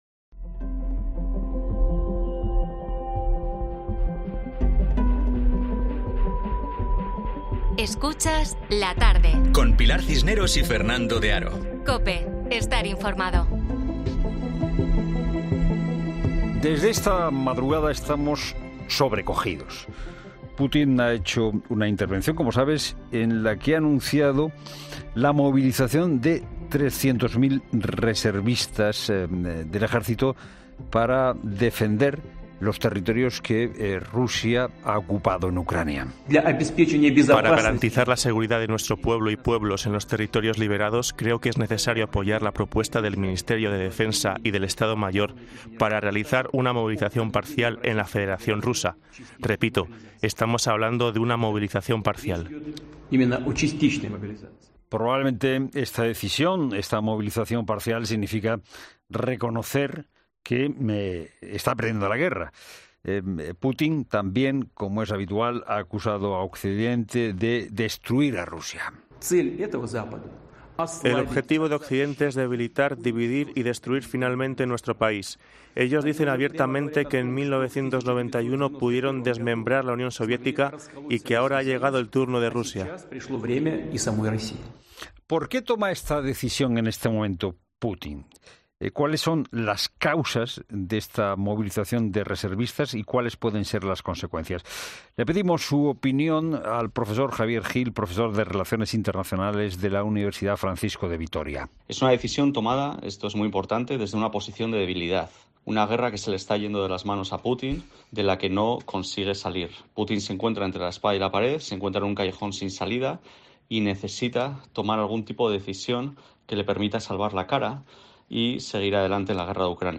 Un experto en COPE, sobre la movilización de militares anunciada por Putin: "Está en un callejón sin salida"